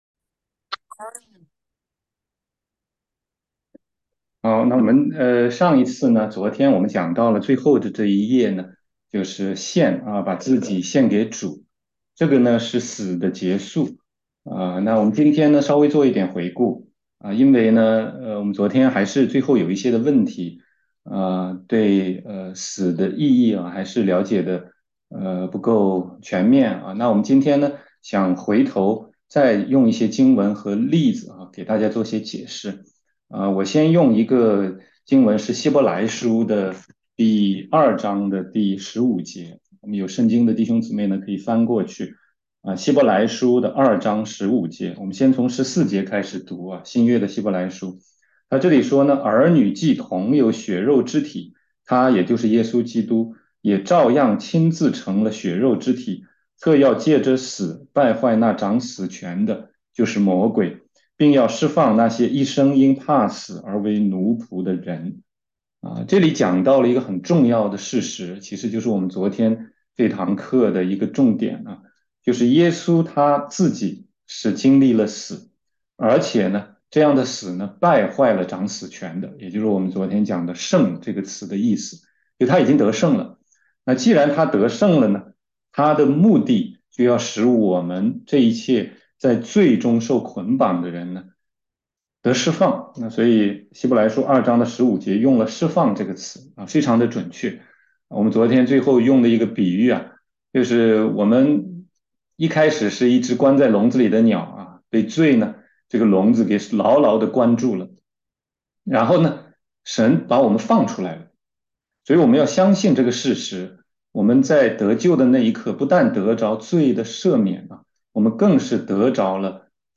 16街讲道录音 - 基督徒成圣之路第二讲：向圣灵活